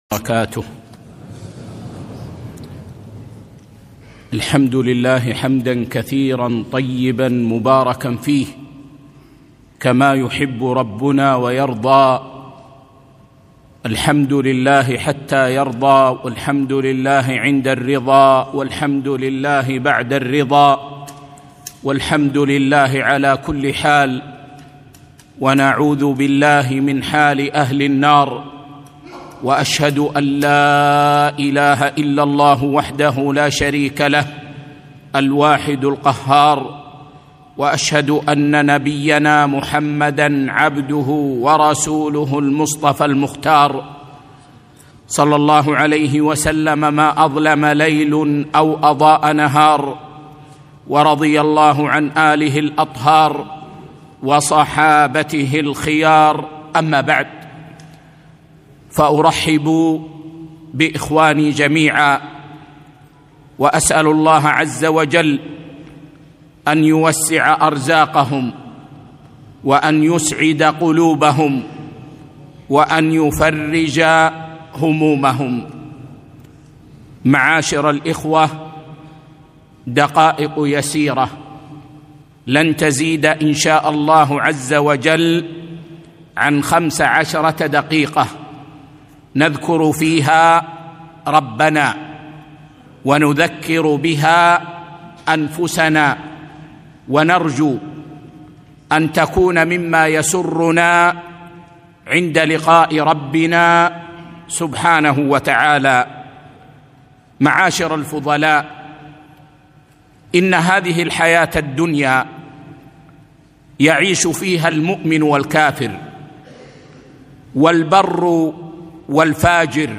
محاضرة - الحياة الطيبة ٢٨ شعبان ١٤٤٥هـ